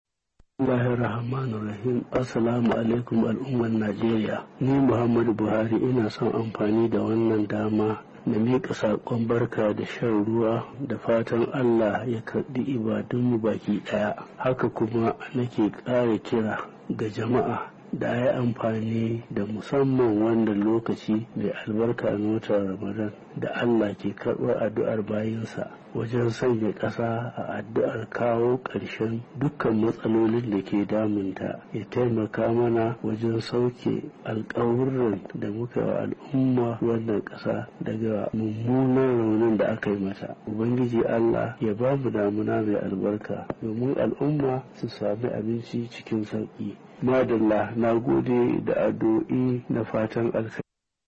Daga birnin Landan kasar Birtaniya inda yake karban jinya saboda ciwon kunne, shugaba Buhari ya aikawa al'ummar Najeriya da sakon godiya da yi masa da kasa fatan alheri musamman cikin wannan wata na azumi